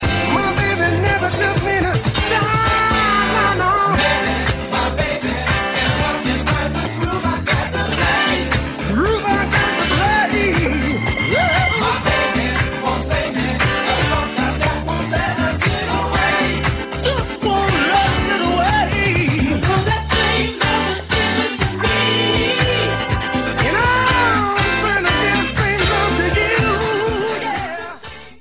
Background vocals, and guitar